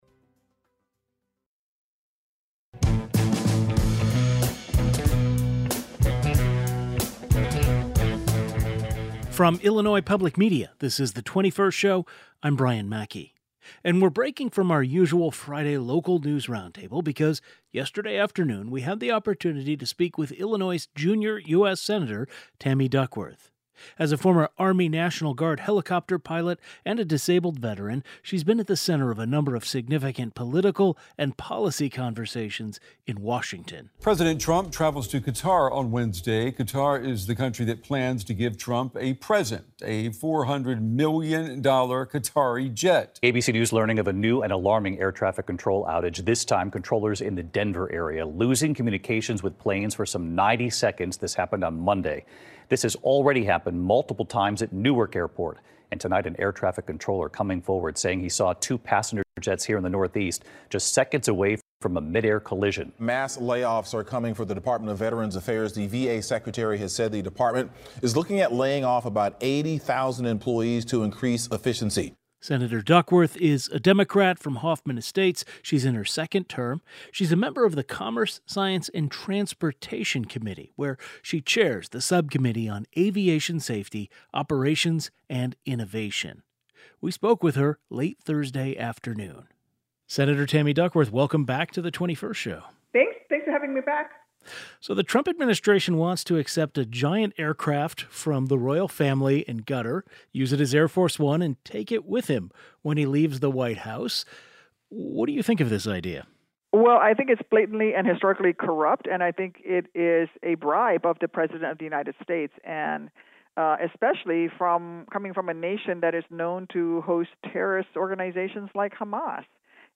A conversation with Illinois U.S. Senator Tammy Duckworth. The former Army National Guard helicopter pilot and disabled veteran talks about President Trump’s plan to accept a $400 million luxury jet from the royal family of Qatar, serious questions about the safety of American aviation, and plans for mass layoffs at the Department of Veterans Affairs.